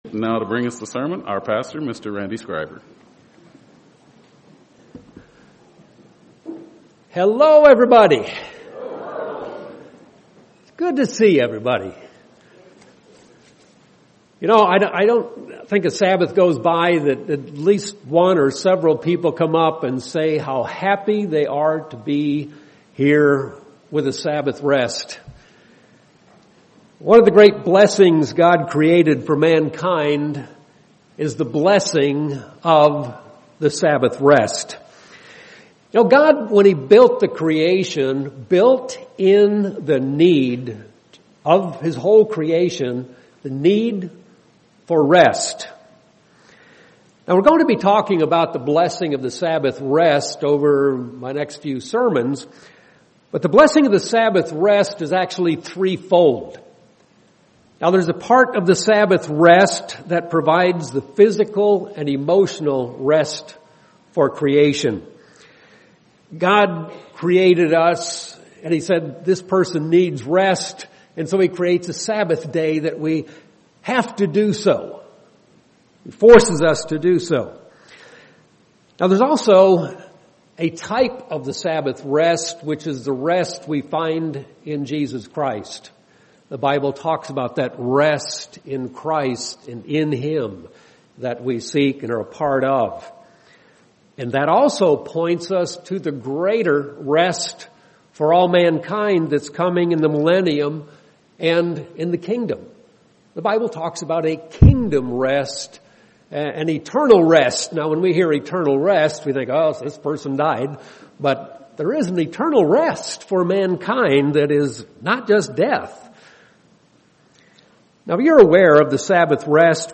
Given in Phoenix East, AZ
UCG Sermon Studying the bible?